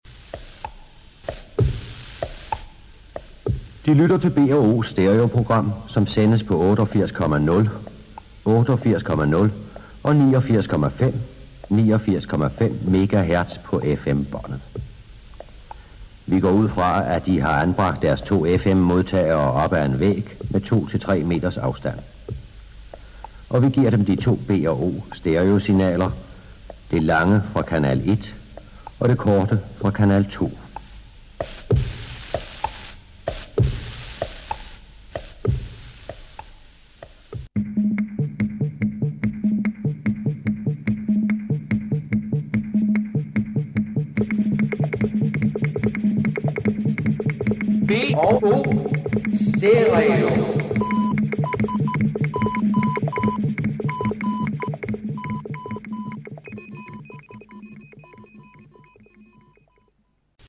B&O utvecklade en avspelningsnål för stereo och med två sändare erhölls stereoeffekt
BO-stereoclip.mp3